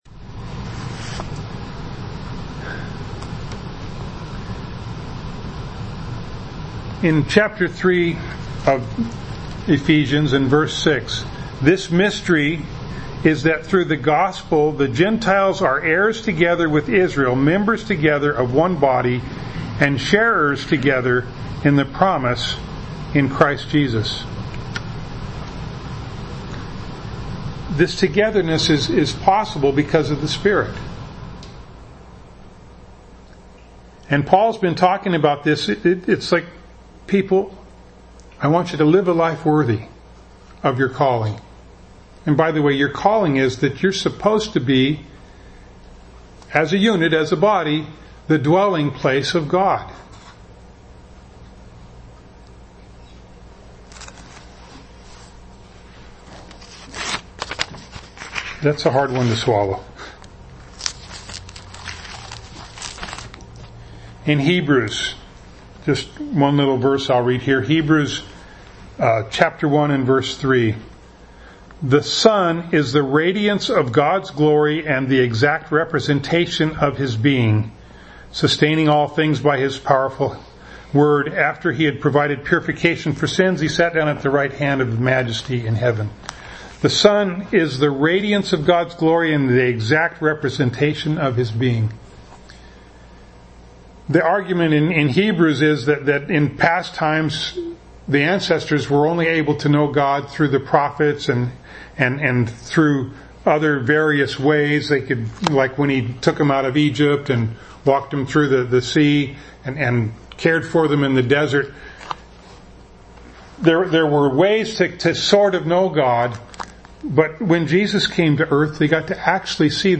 The first portion of this sermon is missing due to technical difficulties.
Service Type: Sunday Morning